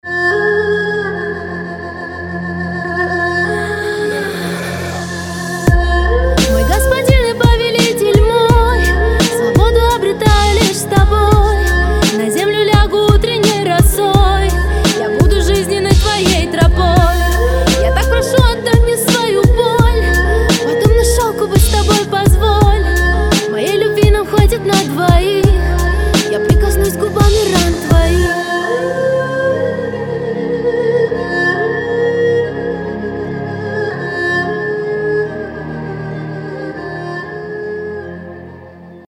лирика
Хип-хоп
романтичные